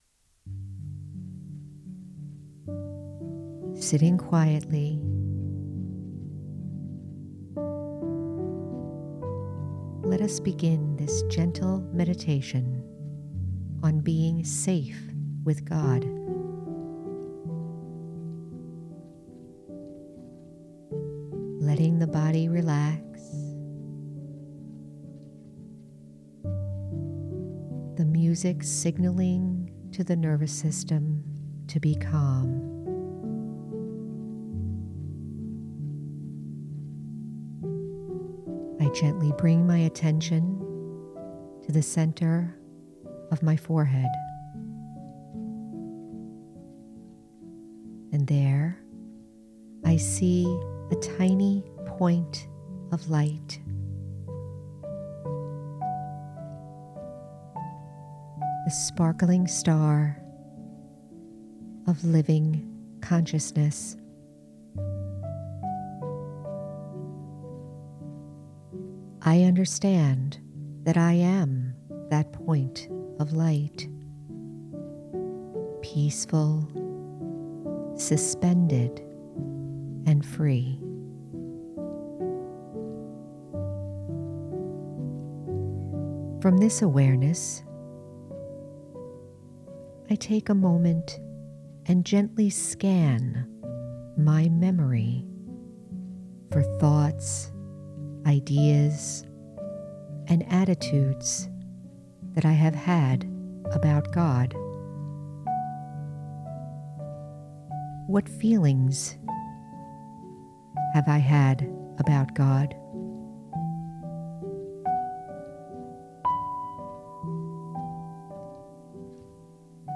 Safe with God- Guided Meditation- The Spiritual American- Episode 149